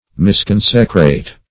misconsecrate - definition of misconsecrate - synonyms, pronunciation, spelling from Free Dictionary
Search Result for " misconsecrate" : The Collaborative International Dictionary of English v.0.48: Misconsecrate \Mis*con"se*crate\, v. t. To consecrate amiss.